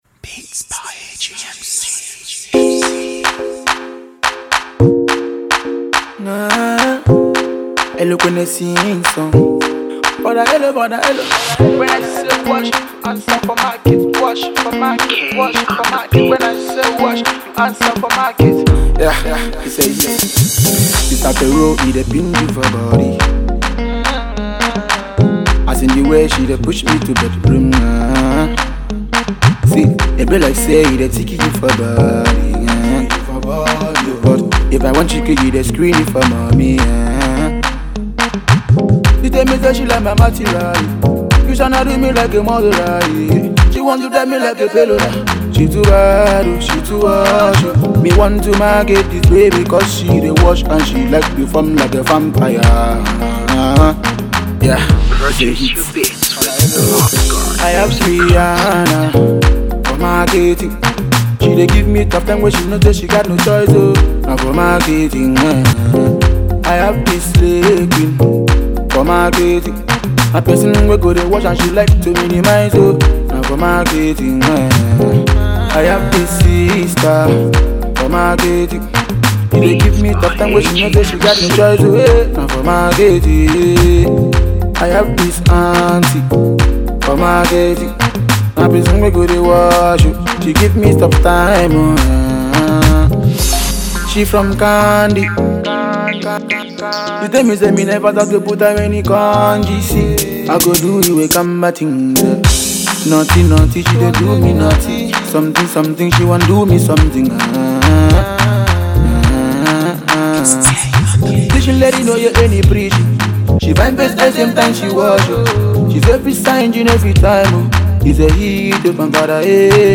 Dance Hall
a sizzling hot, dance-driven song titled